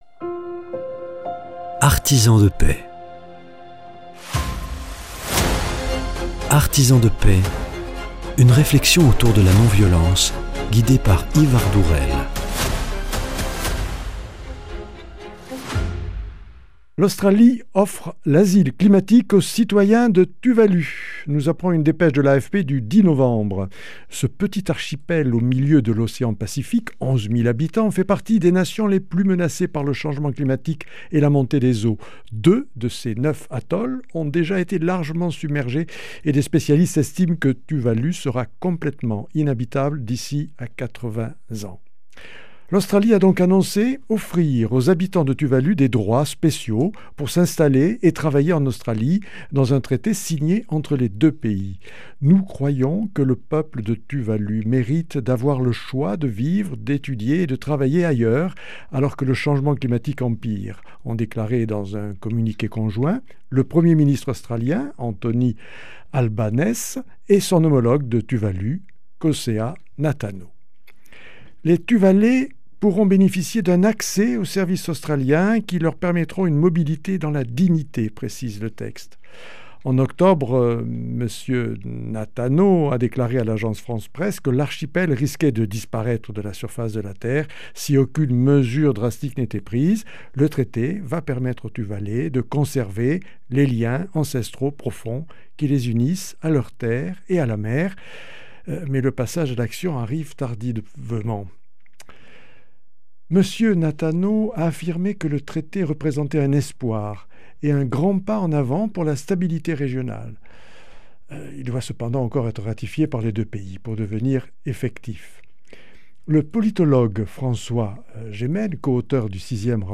Deuxième entretien